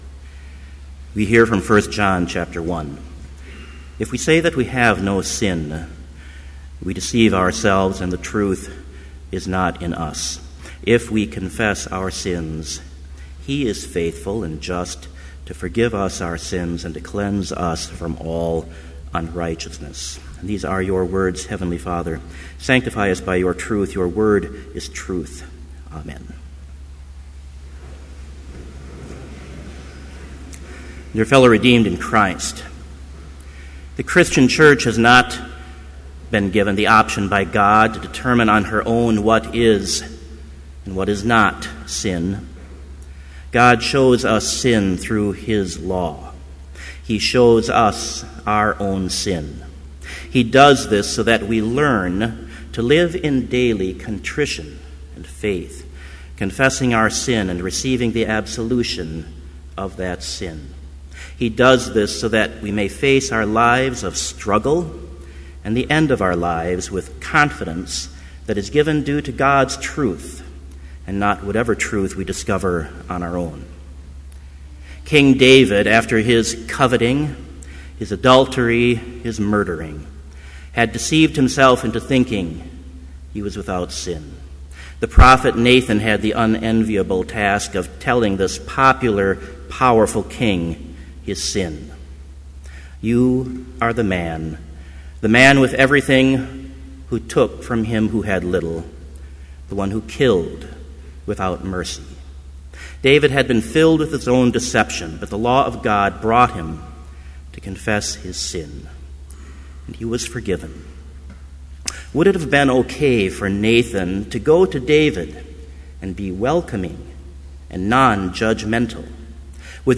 Complete Service
• Hymn 499, vv. 1-3, Now I Have Found the Ground Wherein
• Homily
This Chapel Service was held in Trinity Chapel at Bethany Lutheran College on Tuesday, October 8, 2013, at 10 a.m. Page and hymn numbers are from the Evangelical Lutheran Hymnary.